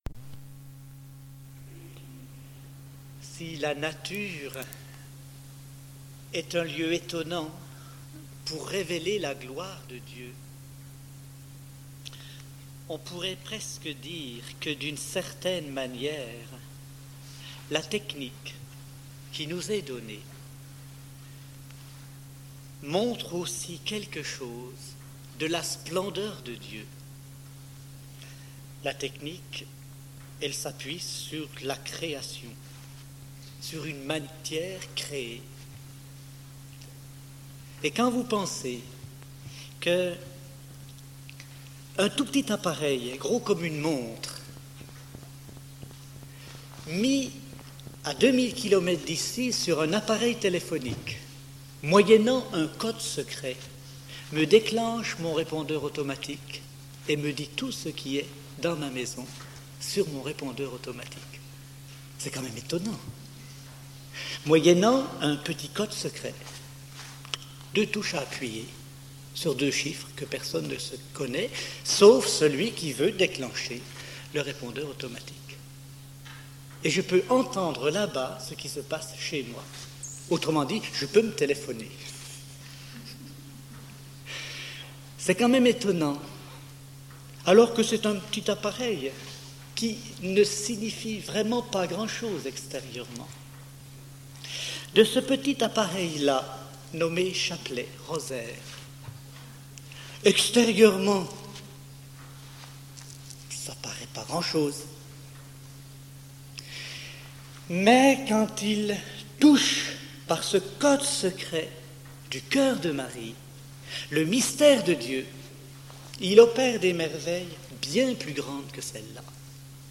(Retraite « Le Rosaire », mai 1992)